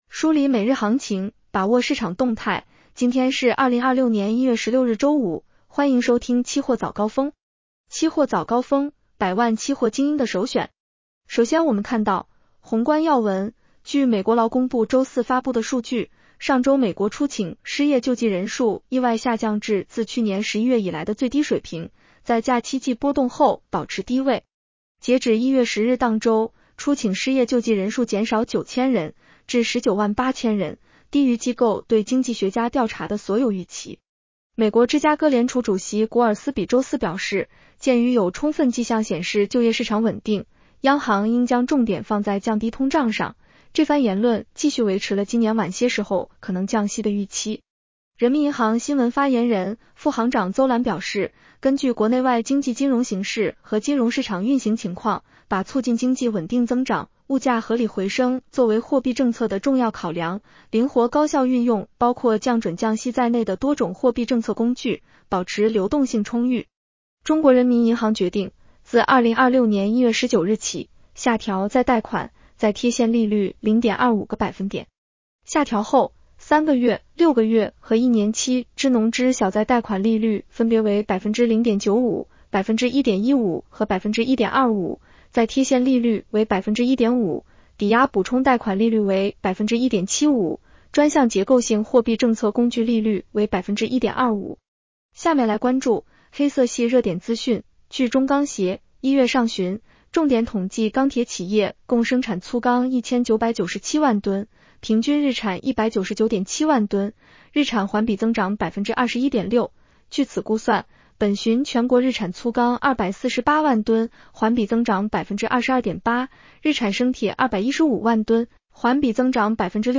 期货早高峰-音频版
期货早高峰-音频版 女声普通话版 下载mp3 热点导读 1.人民银行新闻发言人、副行长邹澜表示，根据国内外经济金融形势和金融市场运行情况，把促进经济稳定增长、物价合理回升作为货币政策的重要考量，灵活高效运用包括降准降息在内的多种货币政策工具，保持流动性充裕。